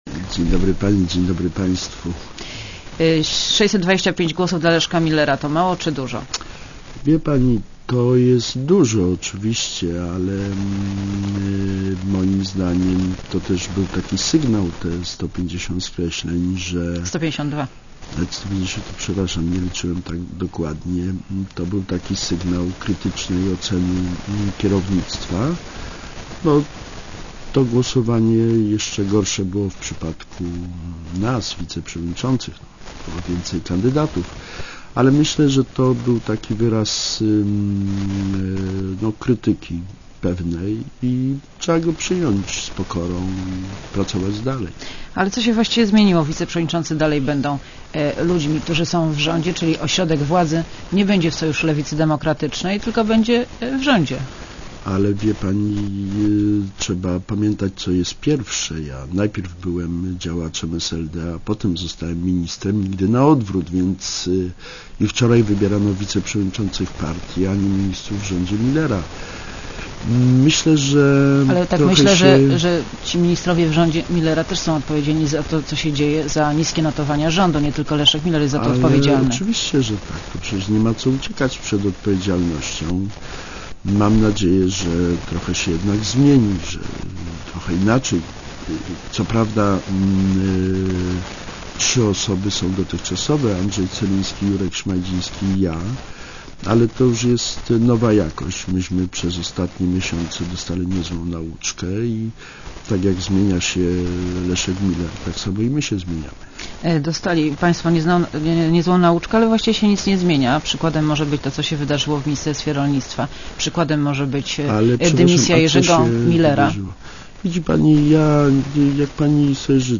Krzysztof Janik w Radiu Zet (RadioZet)